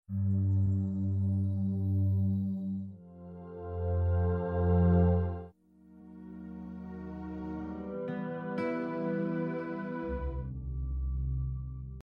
4 frequencies passed. Then the hum began.